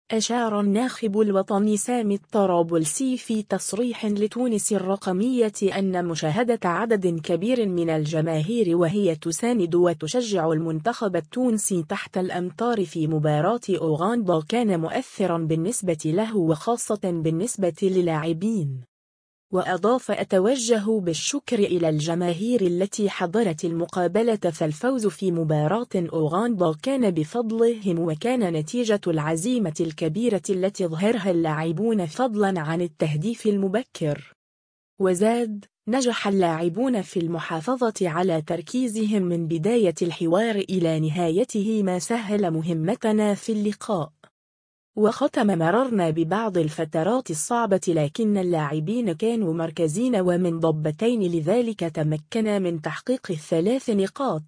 أشار الناخب الوطني سامي الطرابلسي في تصريح لتونس الرقمية أنّ مشاهدة عدد كبير من الجماهير و هي تساند و تشجع المنتخب التونسي تحت الأمطار في مباراة أوغندا كان مؤثرا بالنسبة له و خاصة بالنسبة للاعبين.